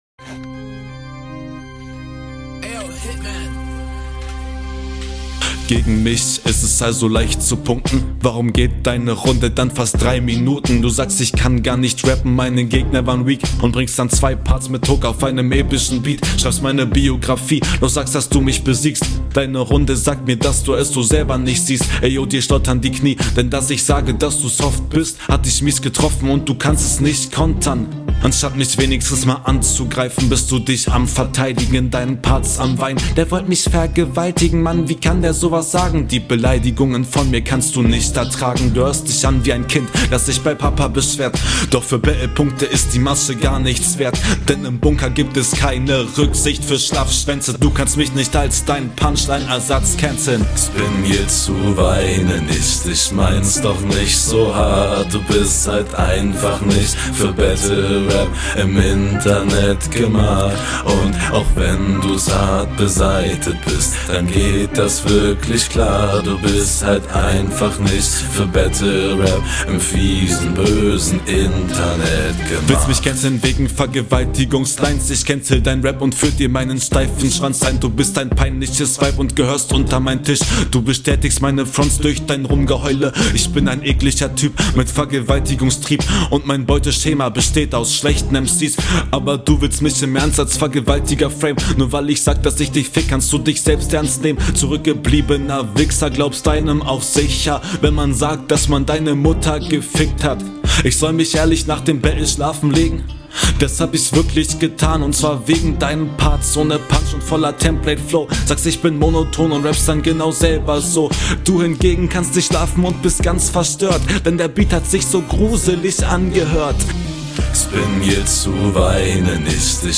Die niedrige Geschwindigkeit steht dir gar nicht gut. Das flowt nicht so ganz.
Du hättest mehr Spielraum mit deinem Stimmdruck gehabt.